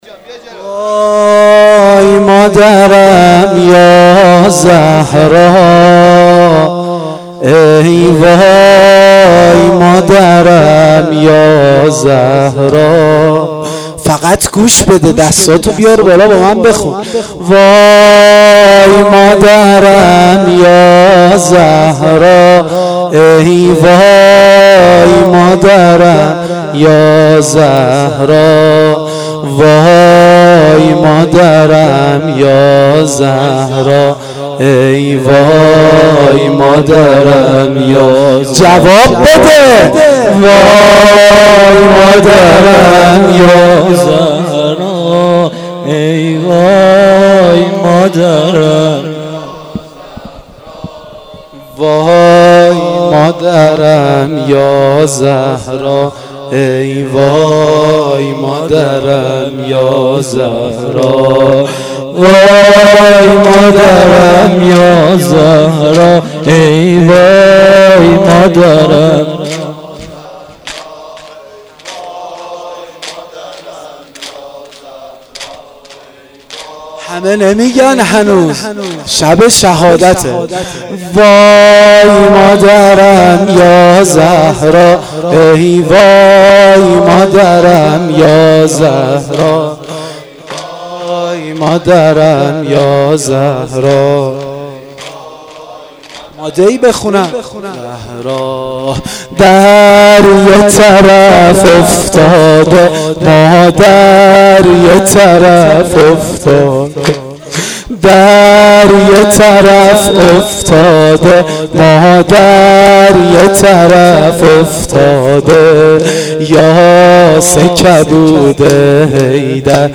زمینه شب دوم فاطمیه دوم